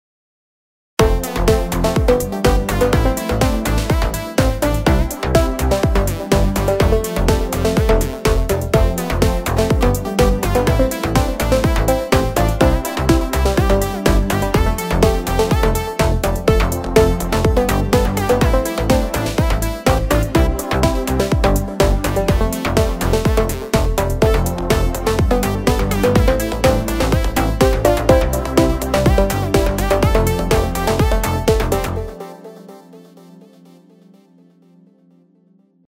תודה!! אני רק עכשיו הבנתי איך לתת קיצת יותר עוצמה לבס ולקיק
התחלתי לאחרונה ללמוד על קיובייס מעניין אותי לשמוע הערות והארות (עדיף לשמוע עם אוזניות) טראק על קיובייס 1.mp3 (דרך אגב רוב הפלאגינים שהורדתי זה מהפורום אז תודה…)